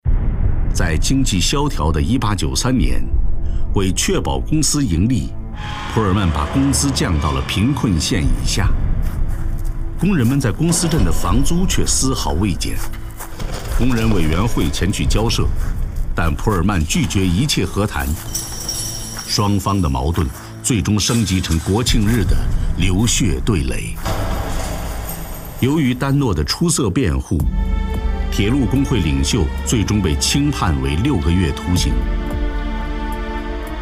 李立宏，男，北京人，著名配音表演艺术家，年度最火纪录片《舌尖上的中国》解说，1986年毕业于北京广播学院播音系。
李立宏_其他_旁白_我们要的未来_讲述.mp3